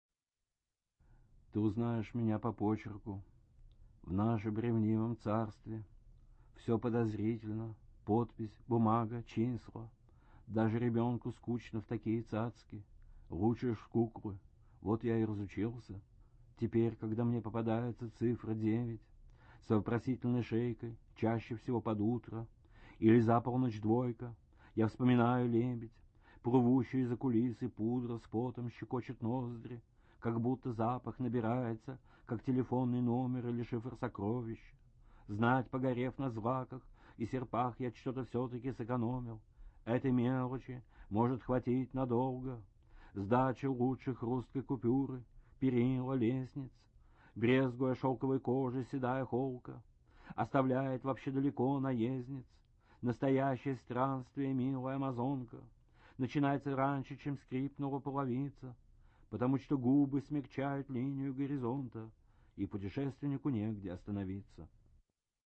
1. «Иосиф Бродский – Ты узнаешь меня по почерку… (читает автор)» /